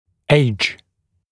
[eɪʤ][эйдж]возраст; стареть, старится